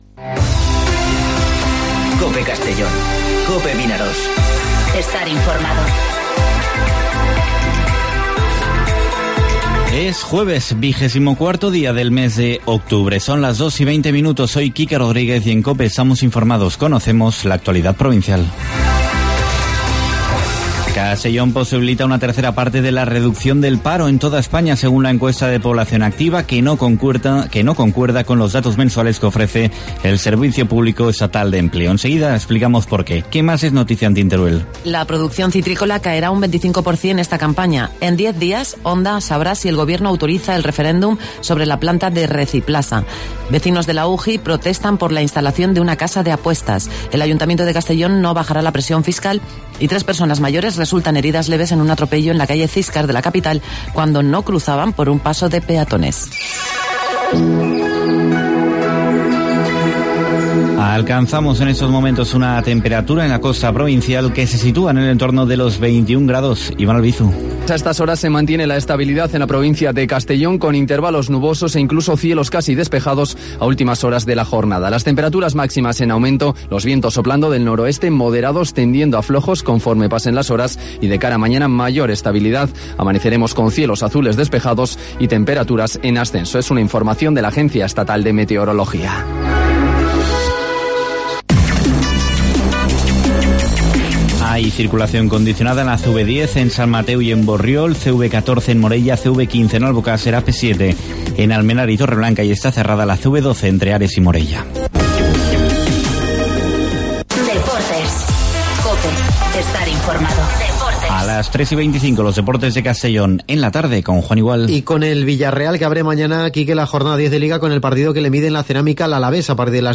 Informativo Mediodía COPE en Castellón (24/10/2019)